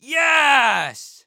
Yees мужской